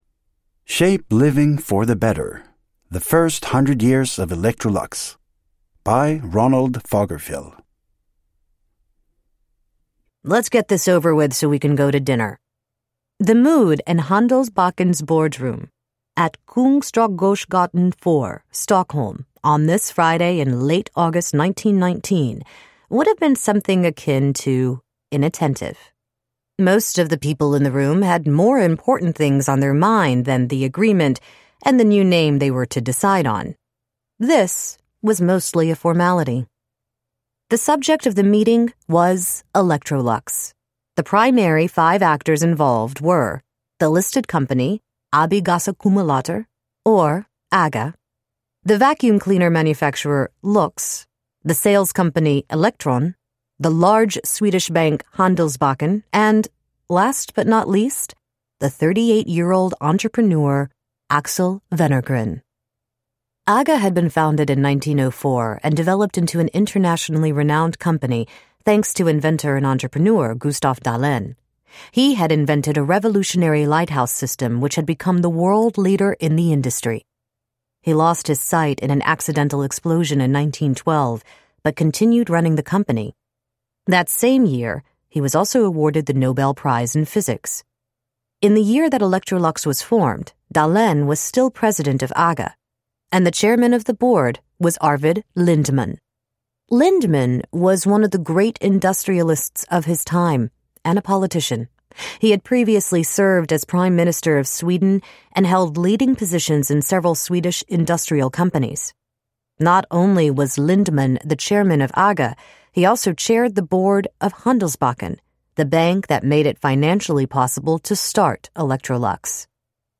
Shape living for the better : the first 100 years of Electrolux – Ljudbok – Laddas ner